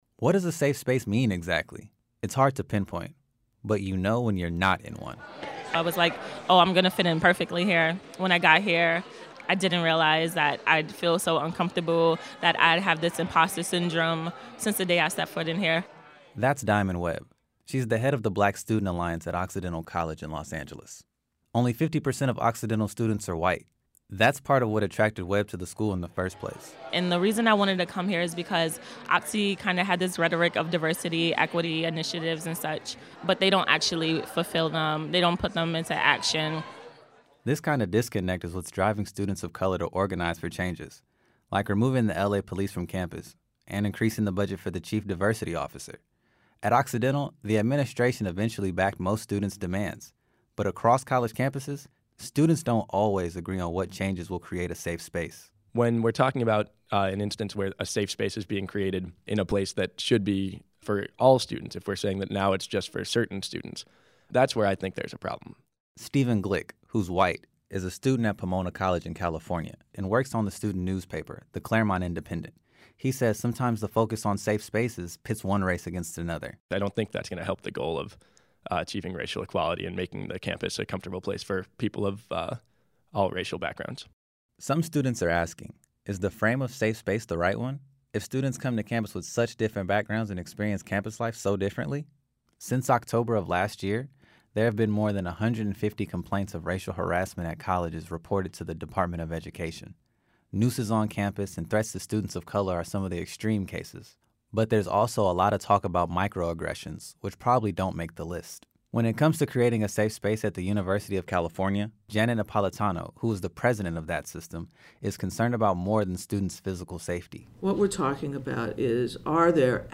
The crowd chanted, “Aint no power like the power of the people.”